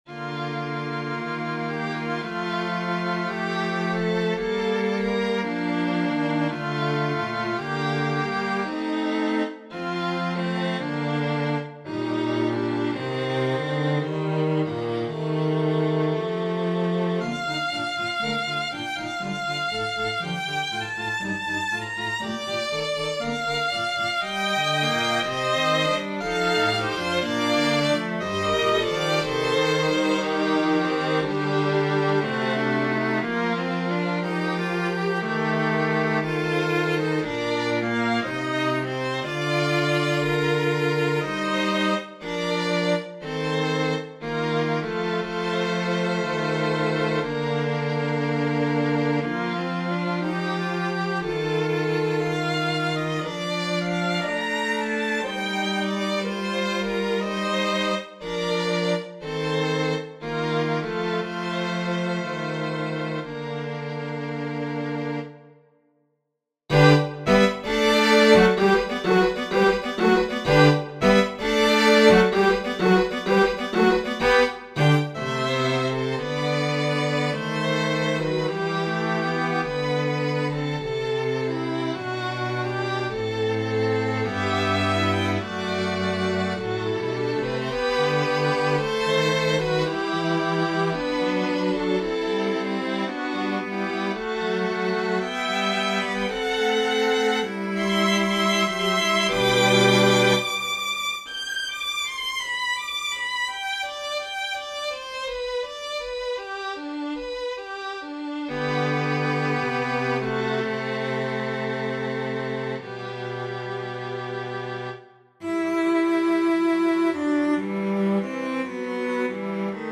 This is the second movement of my string quartet, with this being the slow contrasting movement!